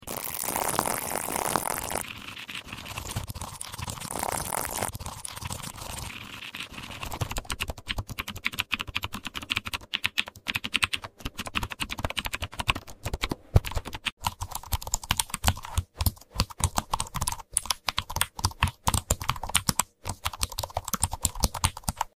Super Fast keyboard typing asmr sound effects free download